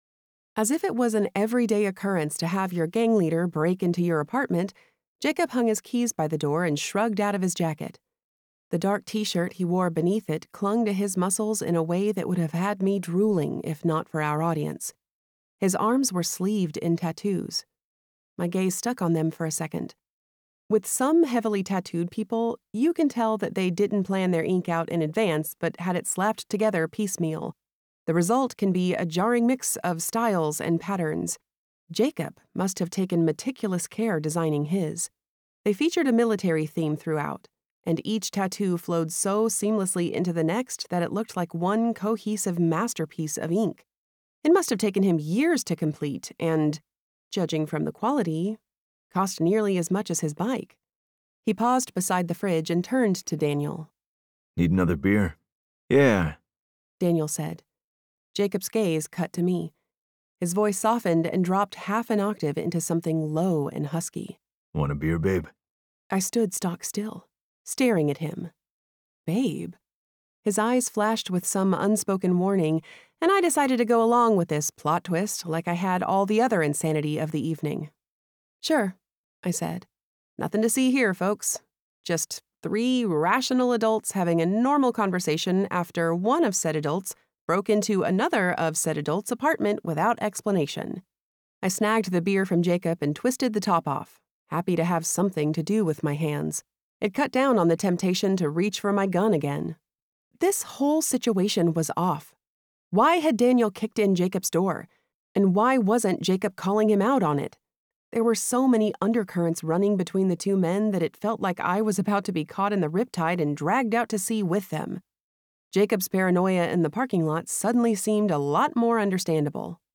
The Kings of Kearny Audiobook